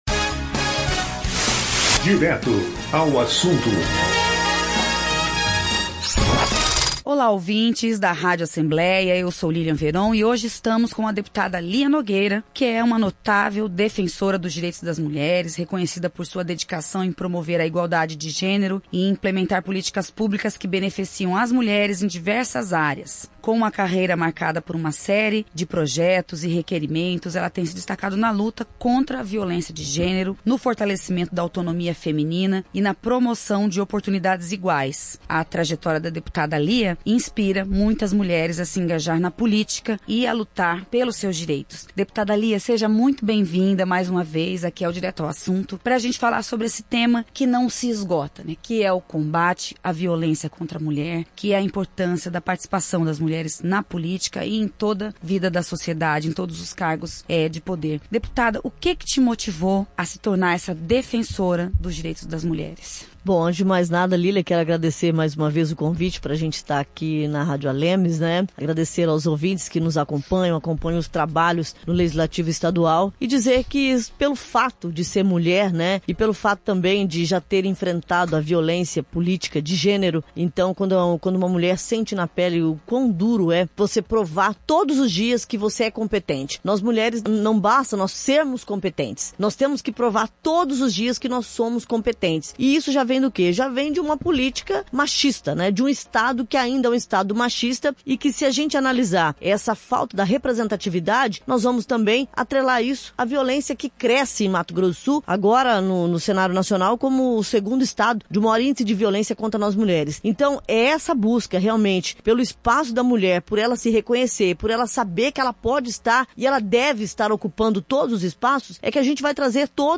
No Direto ao Assunto desta semana, a Rádio ALEMS recebe a deputada estadual Lia Nogueira (PSDB), uma das vozes mais atuantes na defesa dos direitos das mulheres em Mato Grosso do Sul. Reconhecida por sua dedicação em promover a igualdade de gênero e impulsionar políticas públicas em diversas áreas, Lia fala sobre as conquistas já alcançadas e os desafios que ainda precisam ser enfrentados para garantir mais oportunidades, segurança e representatividade para as mulheres sul-mato-grossenses.